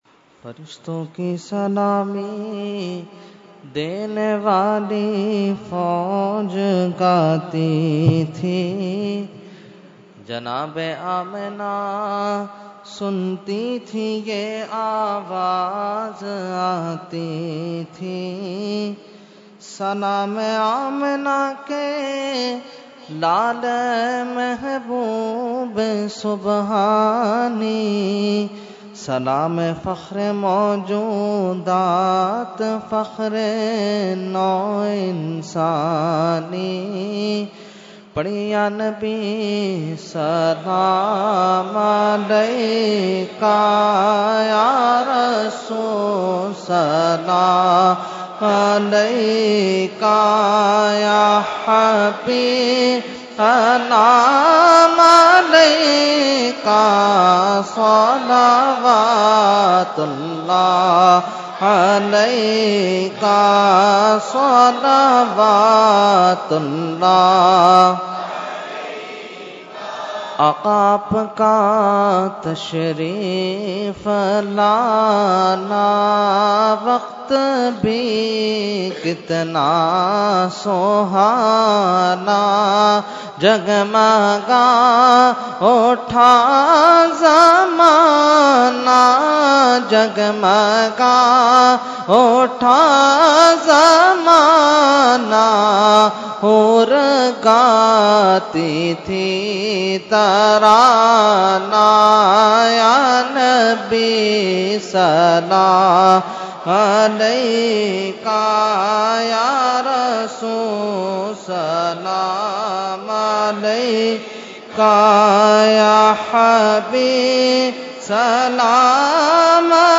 Category : Salam | Language : UrduEvent : Jashne Subah Baharan 2018